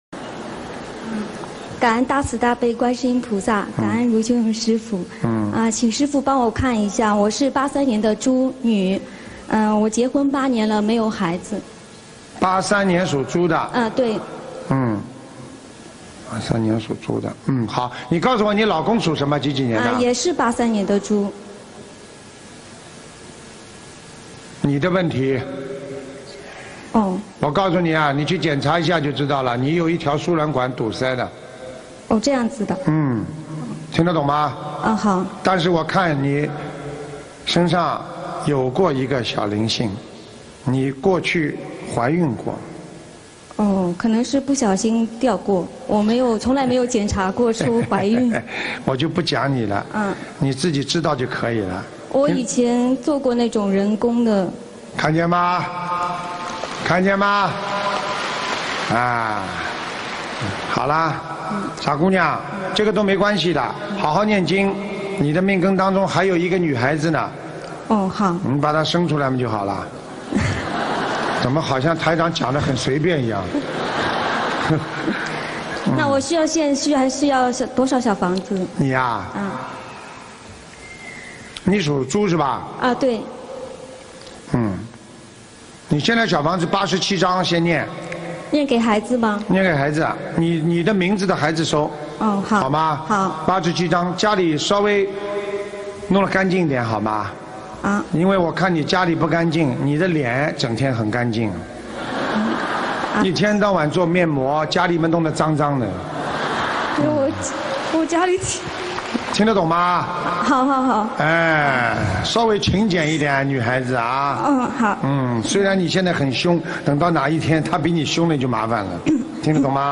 目录：☞ 2013年09月_中国台湾高雄_看图腾_集锦